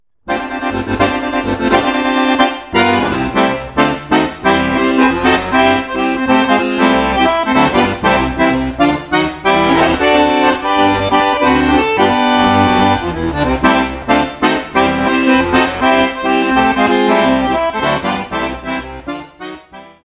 harmonikka accordion